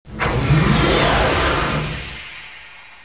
doormove4.wav